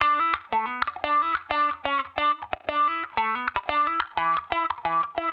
Index of /musicradar/sampled-funk-soul-samples/90bpm/Guitar
SSF_StratGuitarProc2_90E.wav